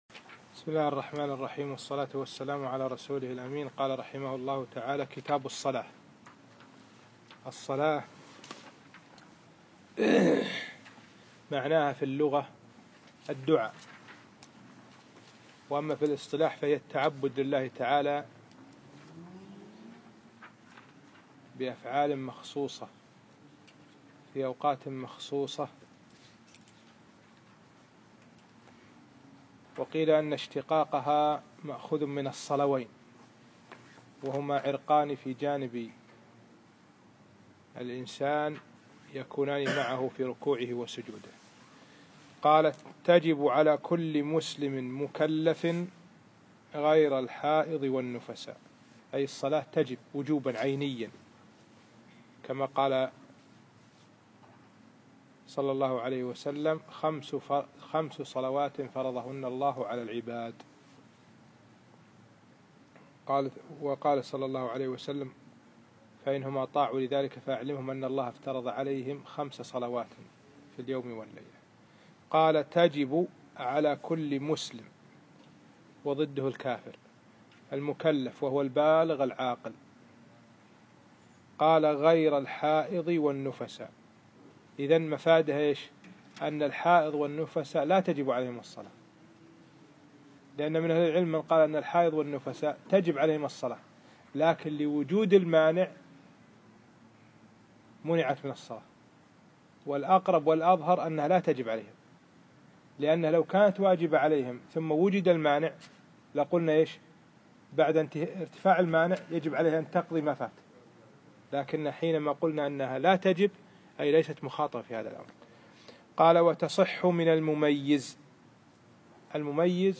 الدرس الخامس